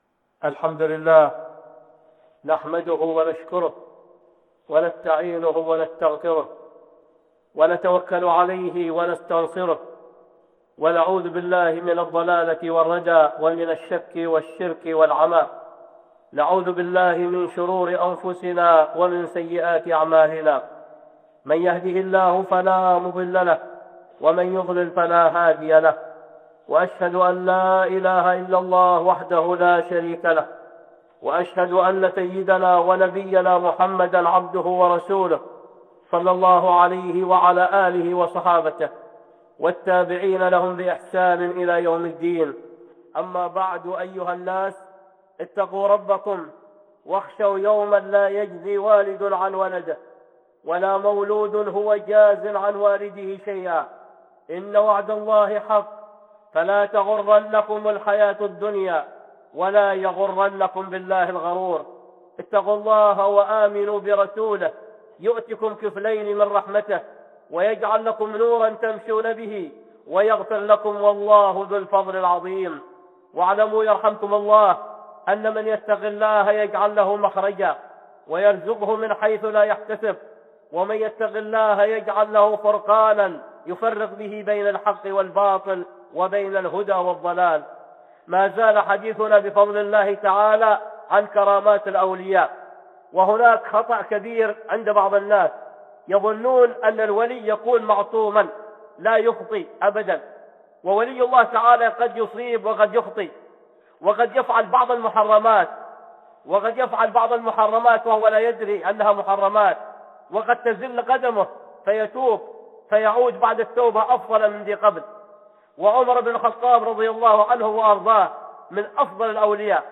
(خطبة جمعة) كرامات الأولياء 2